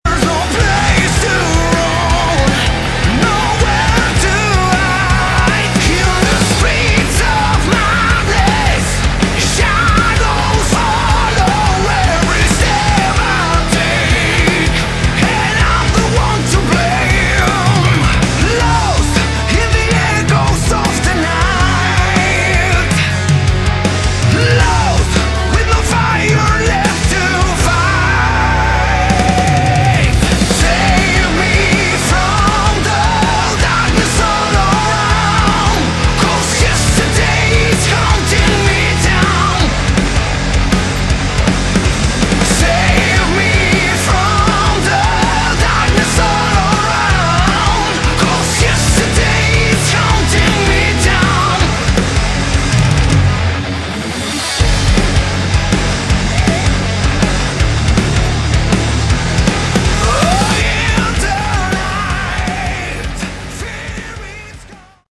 Category: Melodic Metal
Vocals
Guitar, vocals, keyboards
Bass, vocals
Drums